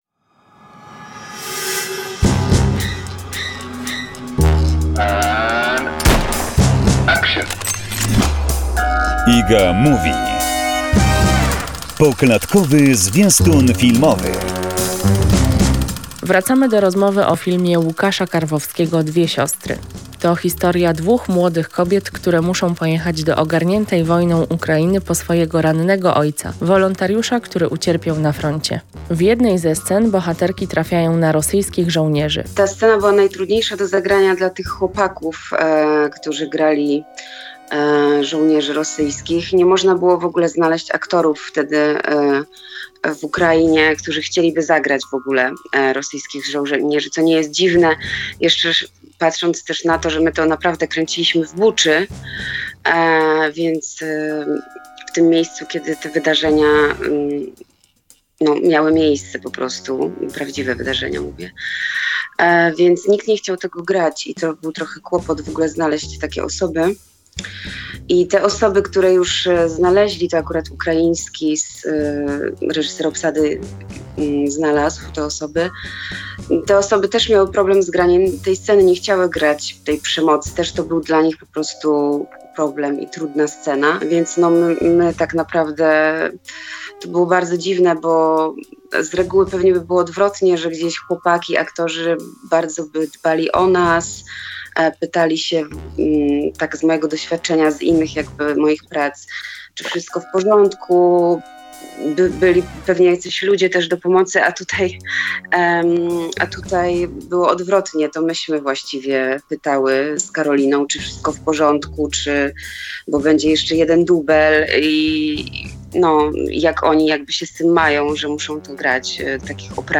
zaprasza do wysłuchania rozmowy